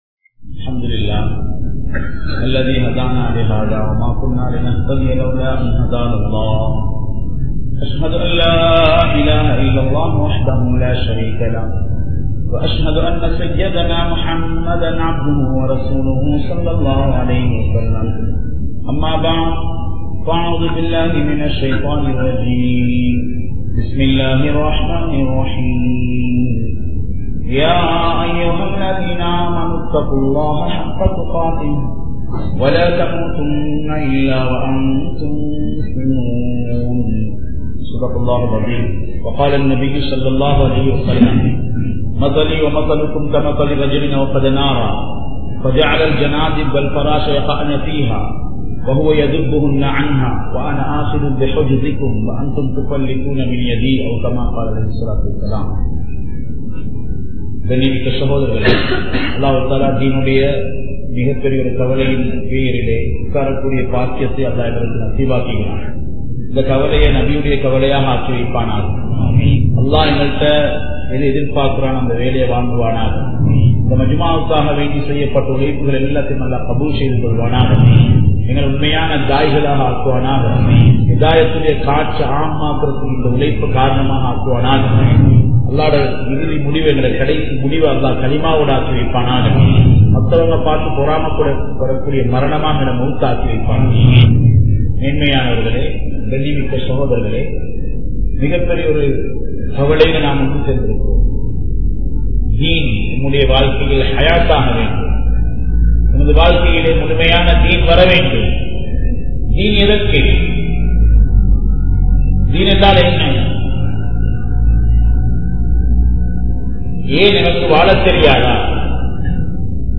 Roohin Payanam (றூஹின் பயணம்) | Audio Bayans | All Ceylon Muslim Youth Community | Addalaichenai